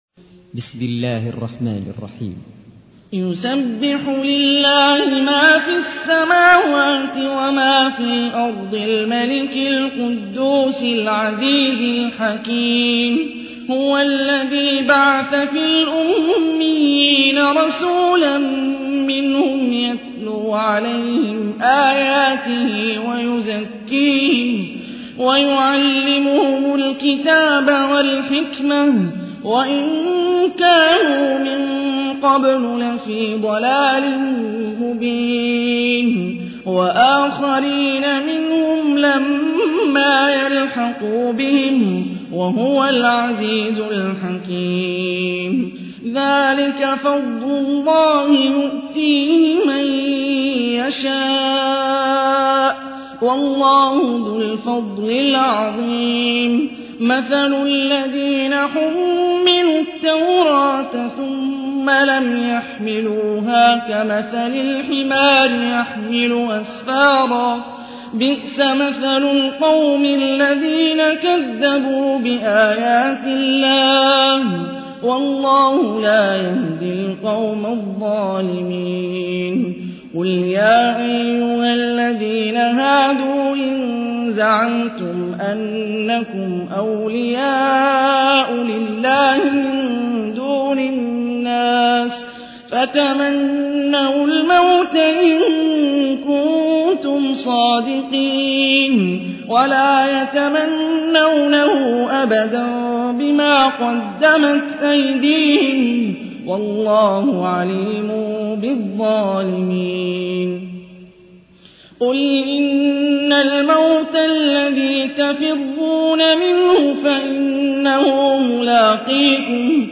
تحميل : 62. سورة الجمعة / القارئ هاني الرفاعي / القرآن الكريم / موقع يا حسين